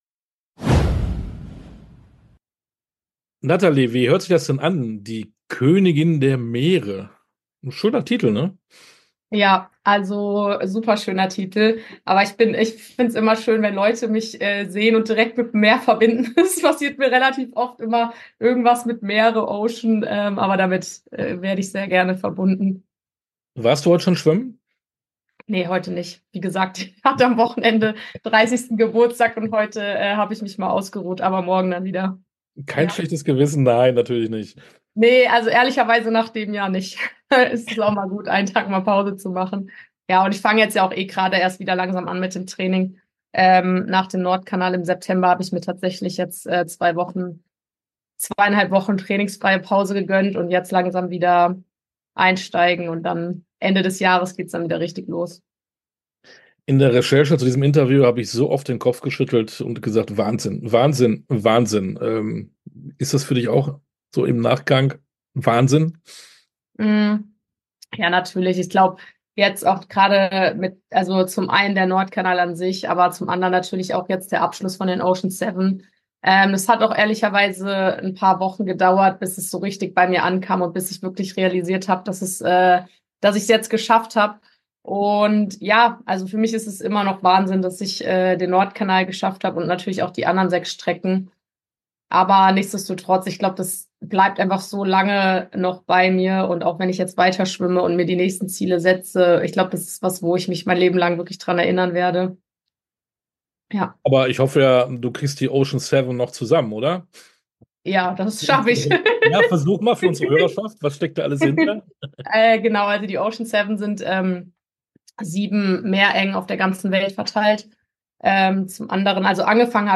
Sportstunde - Interviews in voller Länge